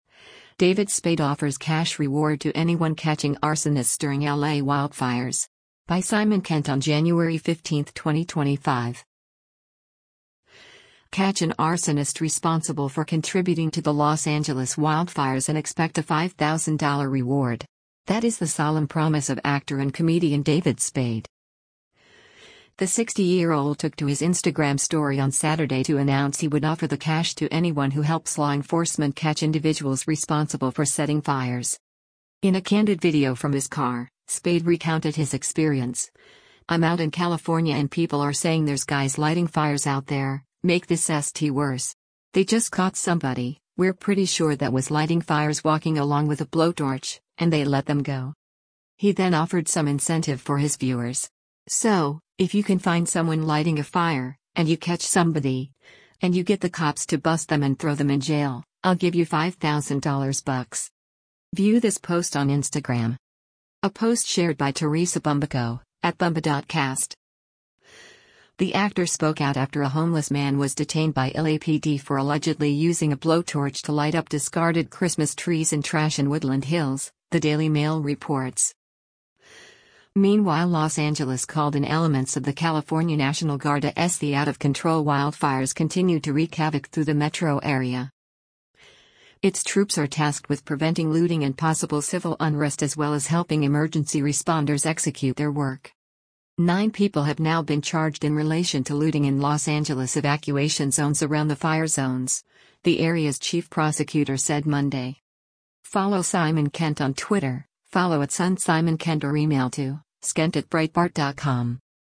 In a candid video from his car, Spade recounted his experience: “I’m out in California and people are saying there’s guys lighting fires out there, make this s**t worse. They just caught somebody, we’re pretty sure that was lighting fires walking along with a blowtorch, and they let them go.”